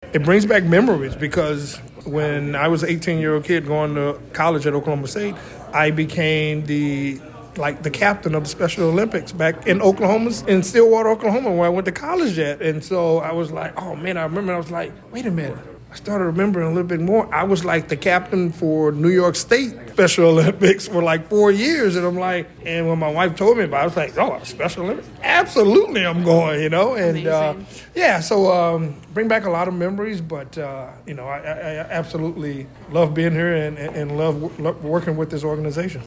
myFM had the chance to speak with some of the head table  guests ahead of the sit-down portion of the evening, who shared why they wanted to get involved in such a special event.